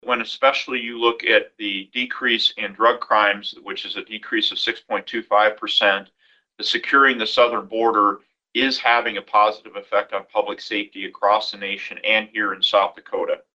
PIERRE, S.D.(HubCityRadio)- On Thursday, Attorney General Marty Jackley gave a report on crime rates in South Dakota for 2025.